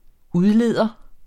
Udtale [ ˈuðˌleˀðʌ ]